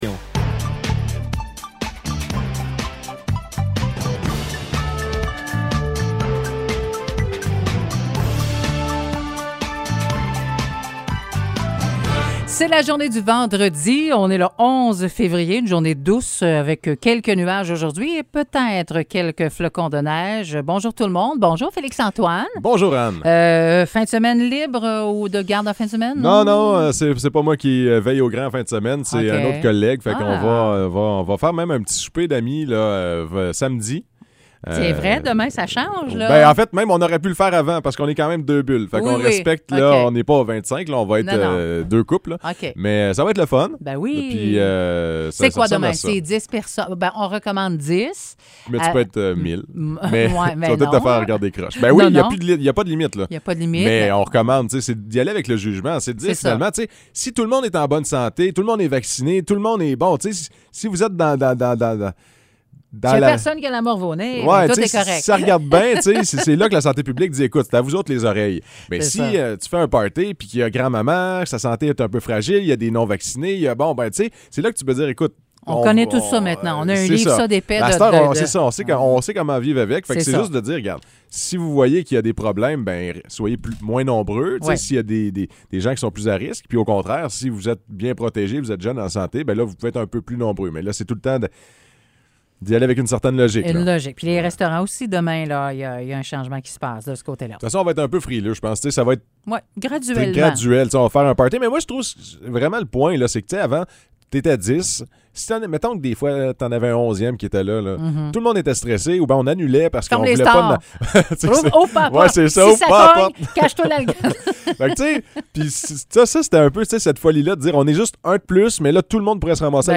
Nouvelles locales - 11 février 2022 - 9 h